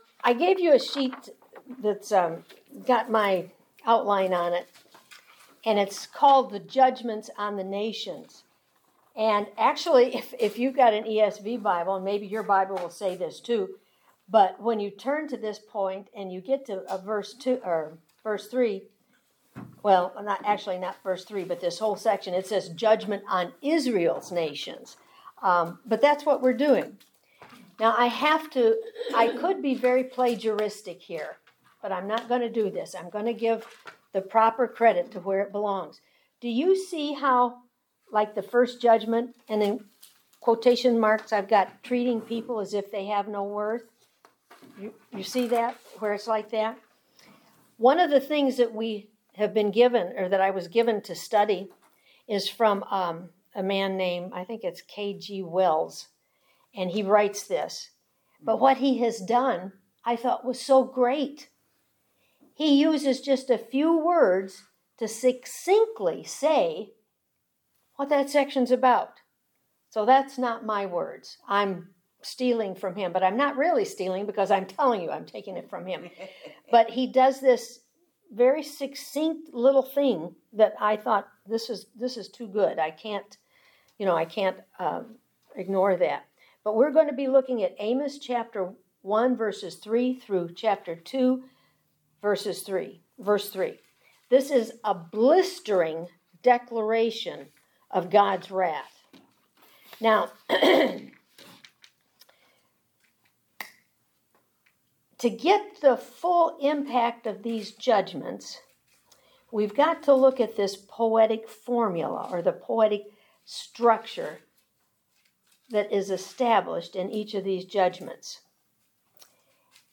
Service Type: Women's Bible Study